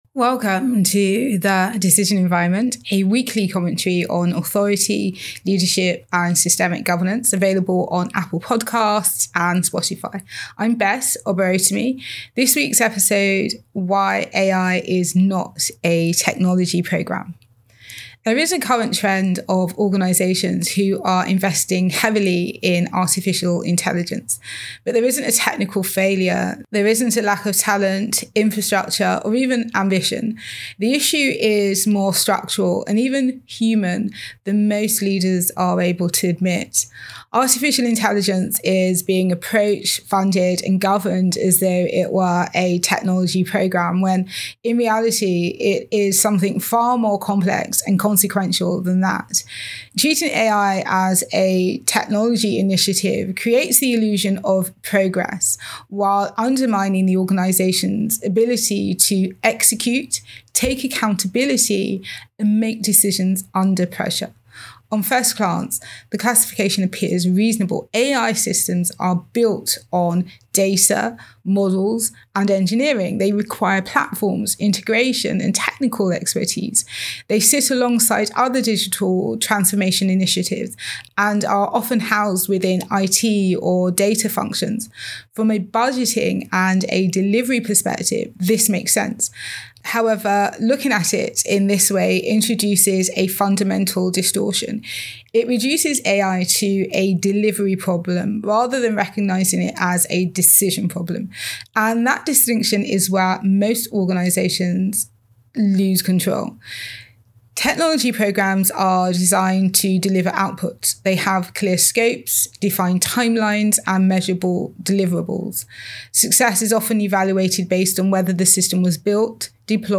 Audio Commentary In today’s commentary, I explore why AI governance continues to fall short in practice.